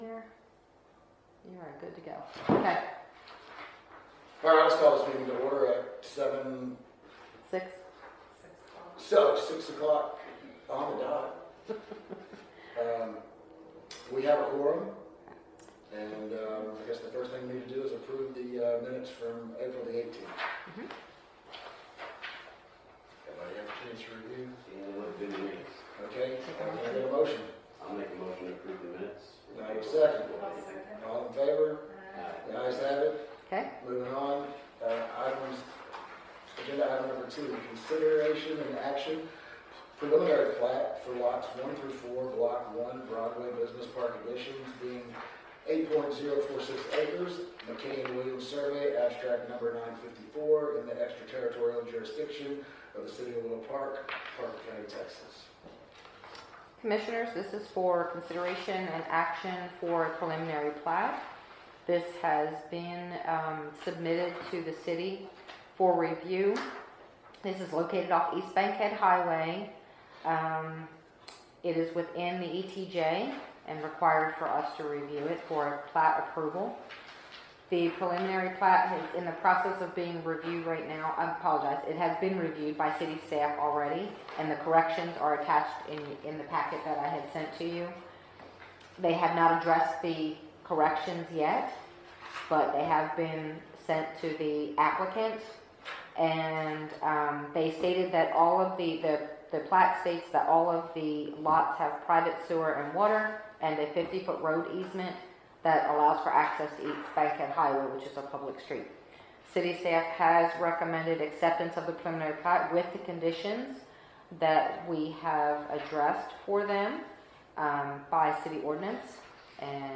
18 July 2023 P&Z Commission Meeting
Location and Time: El Chico City Hall, 120 El Chico, Suite A, Willow Park, Texas 76087, 6PM